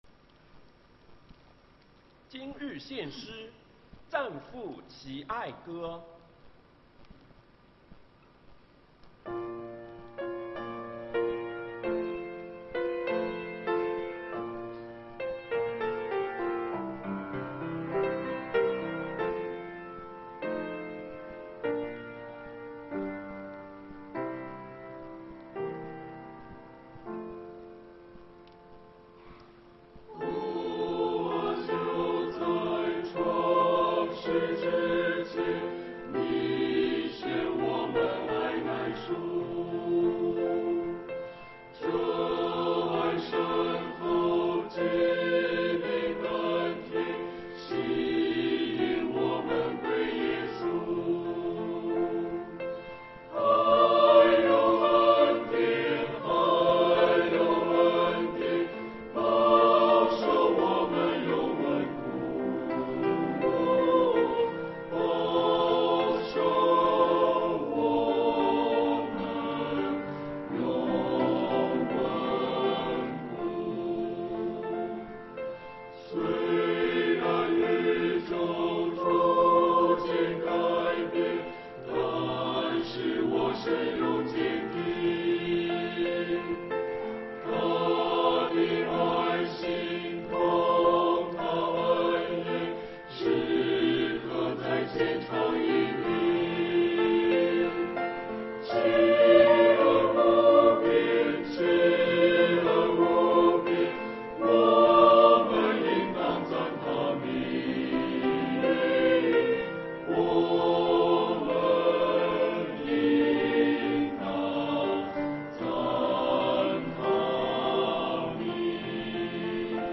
团契名称: 大诗班 新闻分类: 诗班献诗 音频: 下载证道音频 (如果无法下载请右键点击链接选择"另存为") 视频: 下载此视频 (如果无法下载请右键点击链接选择"另存为")